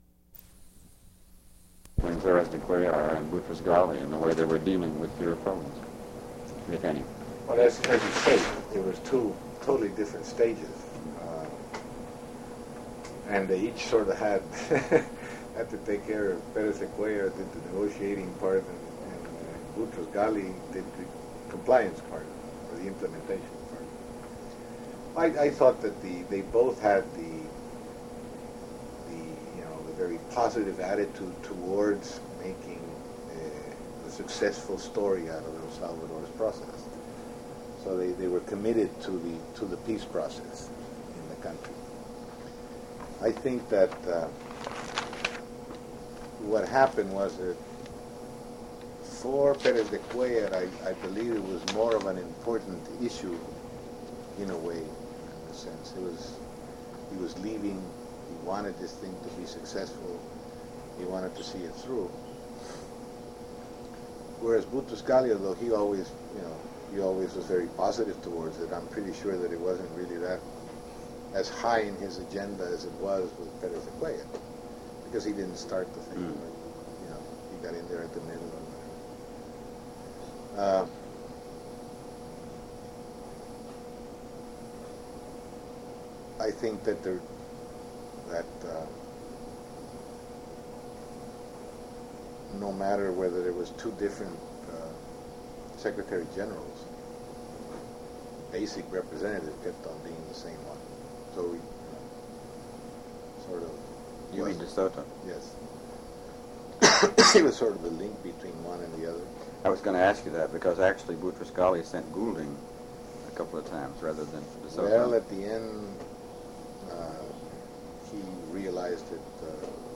Interview with Alfredo Cristiani /